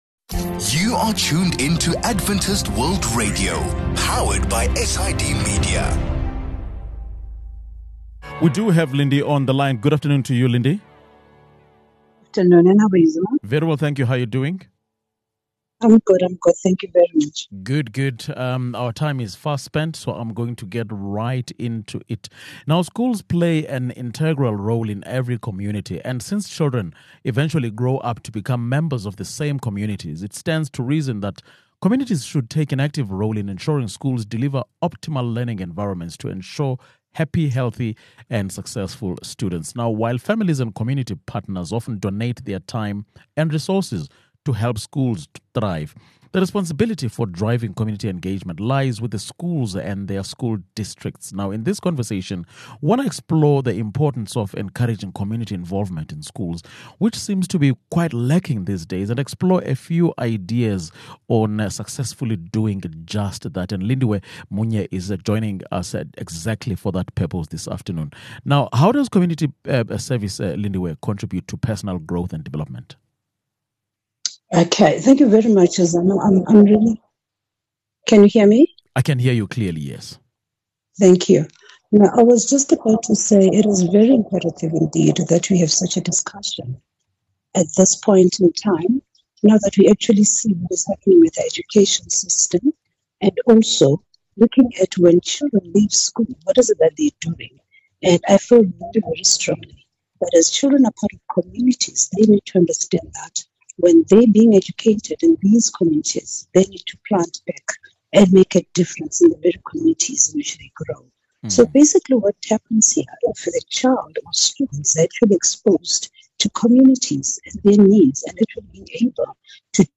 In this conversation, we explore the importance of encouraging community involvement in schools and explore a few ideas on successfully doing just that.